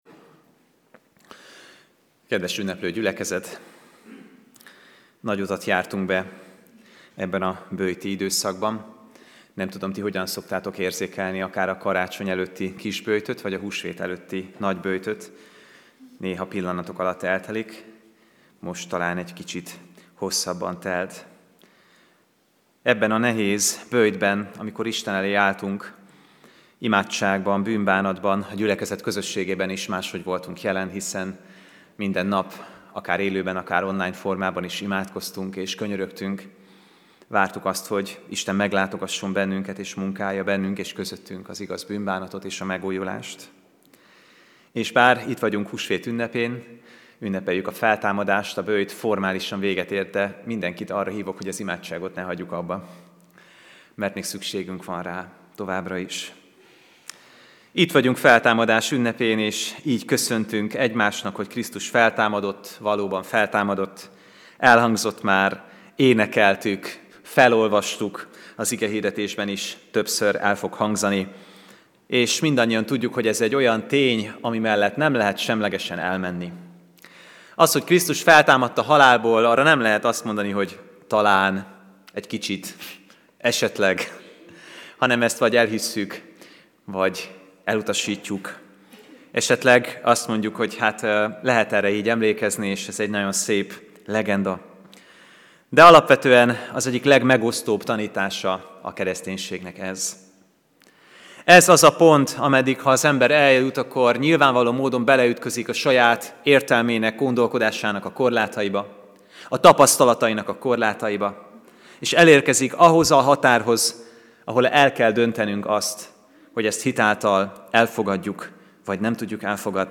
AZ IGEHIRDETÉS LETÖLTÉSE PDF FÁJLKÉNT AZ IGEHIRDETÉS MEGHALLGATÁSA
Lekció: Jn 20,1-10/Textus: Zsolt 39,6b 2024. március 31. Húsvét vasárnap